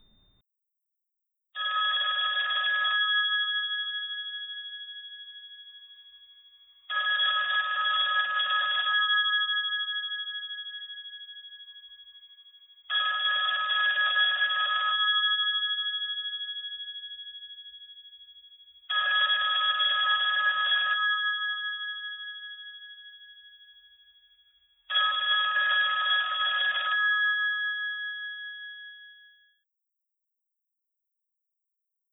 Melodie vyzvánění
klasicc.wav